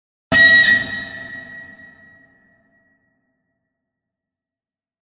Звук электрического сигнала в поезде